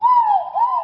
bird_injured.mp3